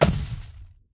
Minecraft Version Minecraft Version 1.21.5 Latest Release | Latest Snapshot 1.21.5 / assets / minecraft / sounds / entity / shulker_bullet / hit1.ogg Compare With Compare With Latest Release | Latest Snapshot